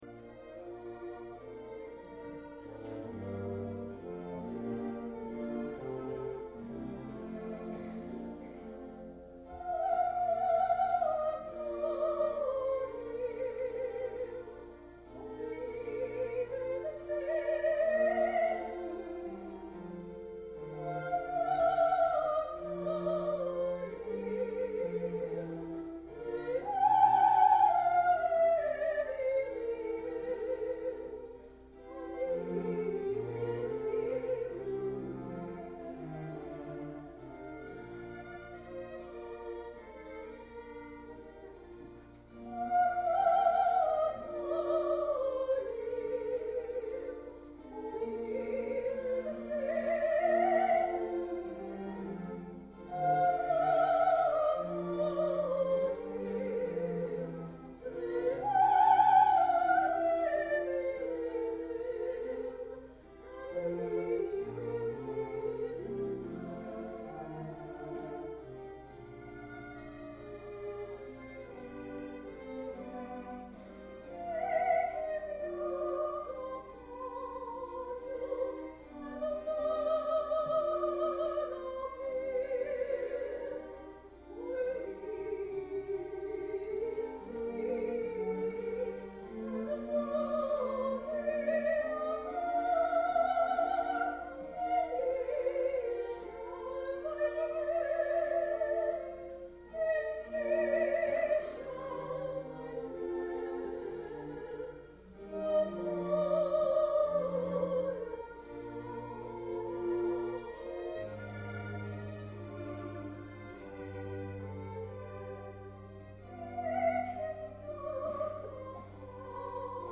Opera/Classical